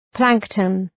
Προφορά
{‘plæŋktən}